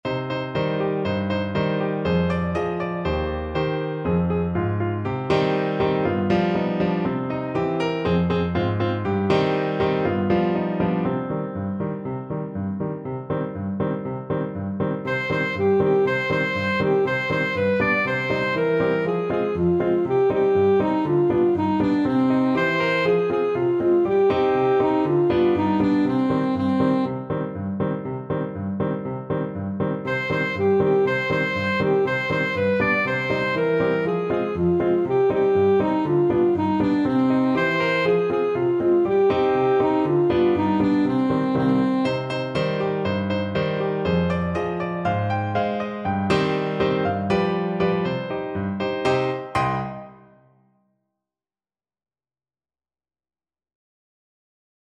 Alto Saxophone version
Alto Saxophone
2/4 (View more 2/4 Music)
C minor (Sounding Pitch) A minor (Alto Saxophone in Eb) (View more C minor Music for Saxophone )
Allegro (View more music marked Allegro)
Classical (View more Classical Saxophone Music)
oi_marichko_ASAX.mp3